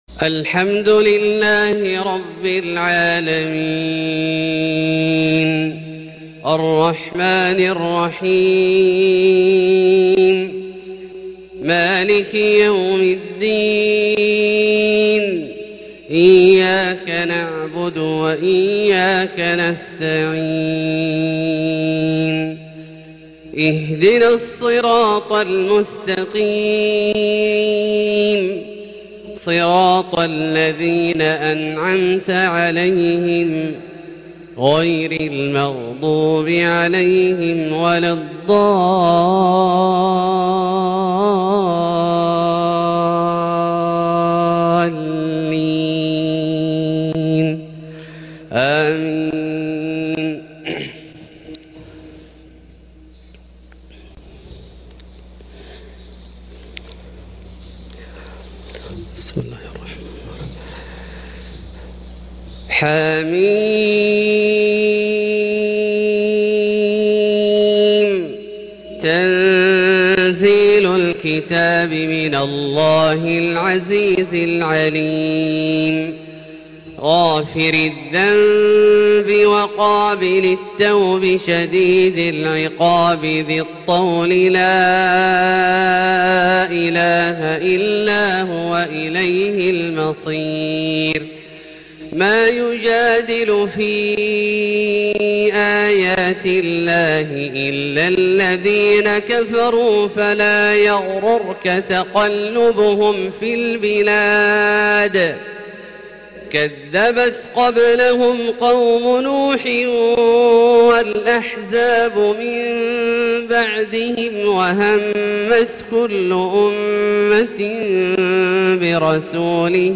صلاة الفجر 8-7-1442 تلاوة من سورة غافر[1-20] > ١٤٤٢ هـ > الفروض - تلاوات عبدالله الجهني